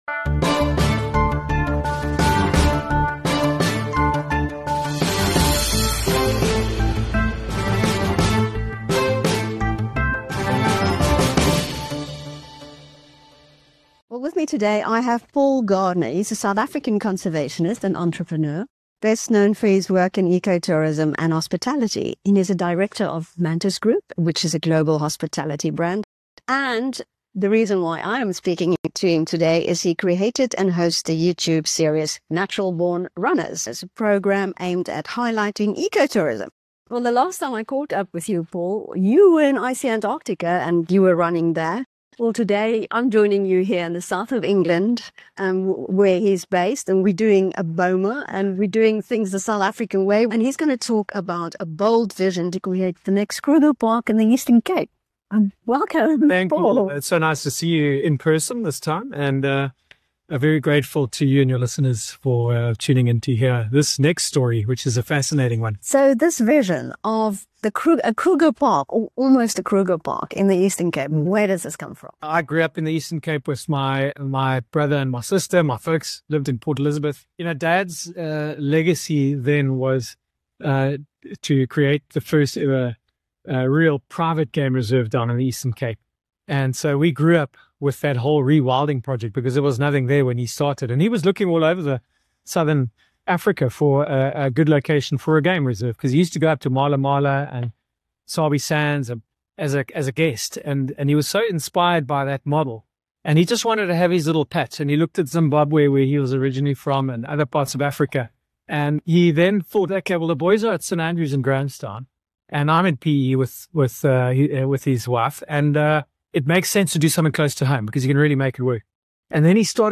Speaking to BizNews during a fireside chat in England